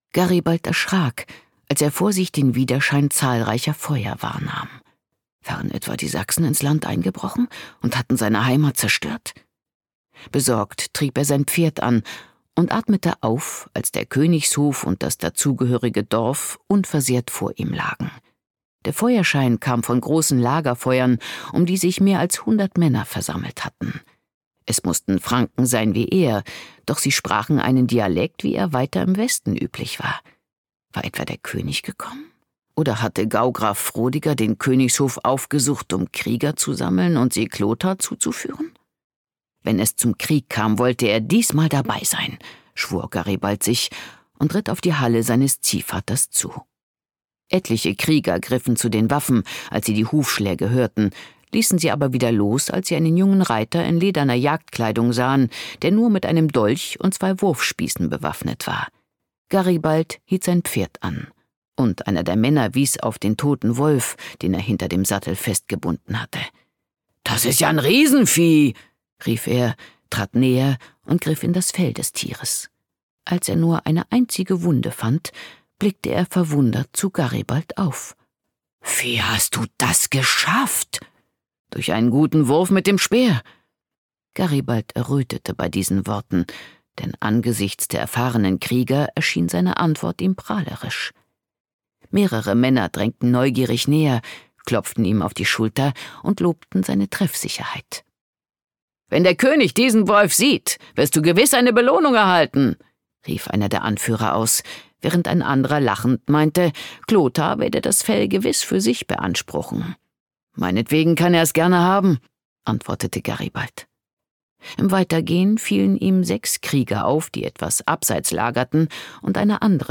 Das historische Hörbuch Der Krieger und die Königin erzählt die Geschichte des ruhmreichen Kriegers Garibald und der Langobarden-Prinzessin Waltrada.